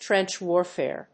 アクセントtrénch wàrfare